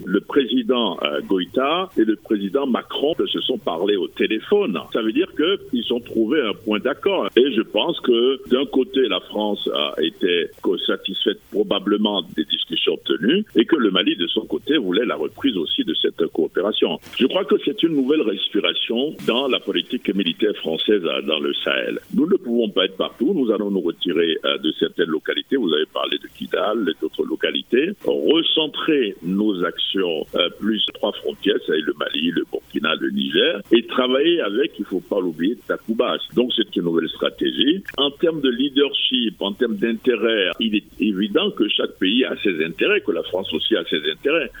spécialiste de questions sécuritaires :